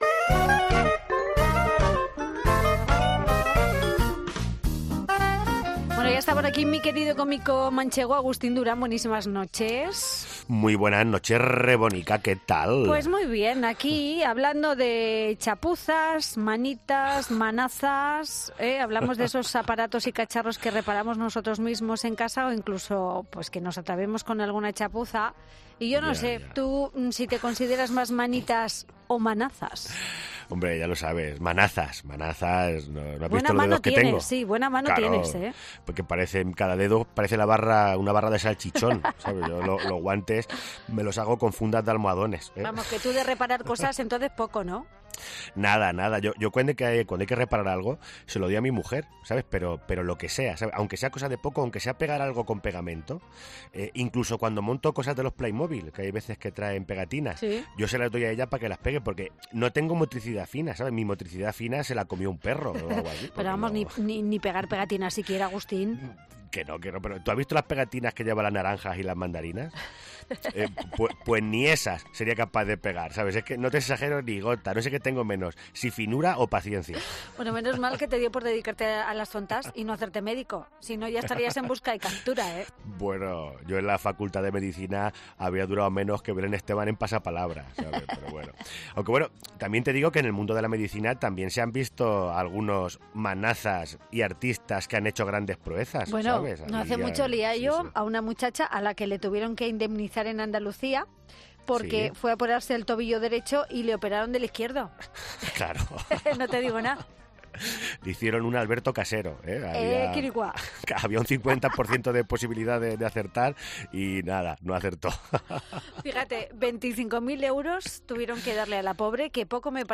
AUDIO: Nuestro cómico manchego favorito nos relata por que no tiene dedos, sino barras de salchichón